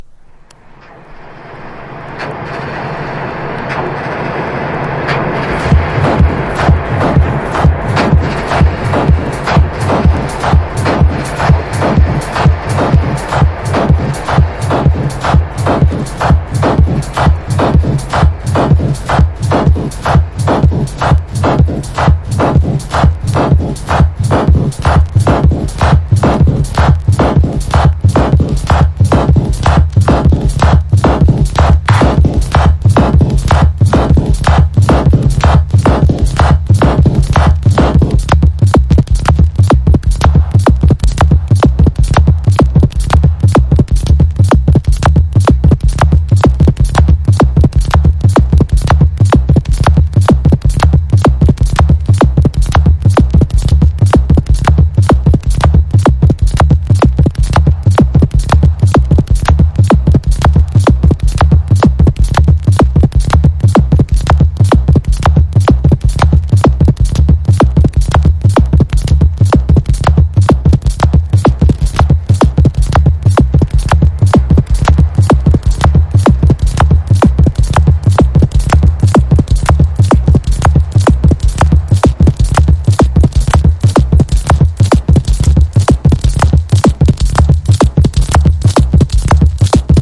TECHNO / DETROIT / CHICAGO# DUB / LEFTFIELD